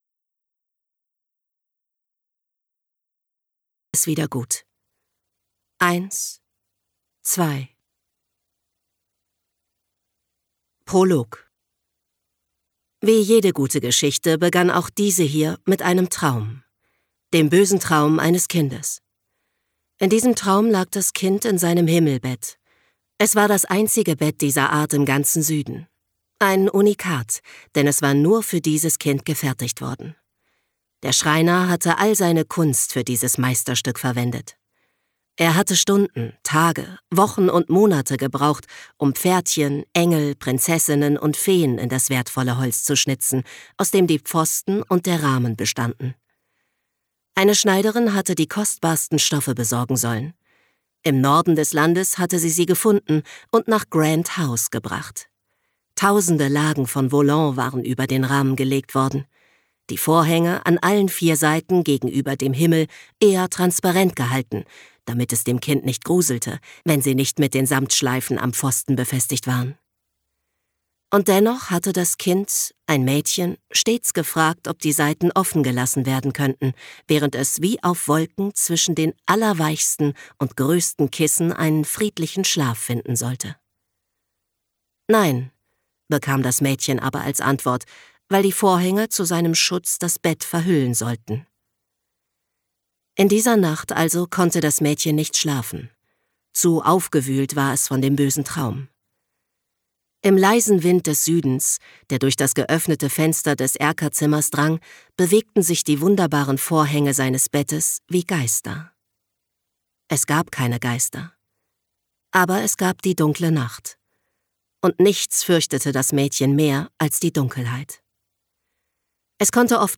Hörbuch: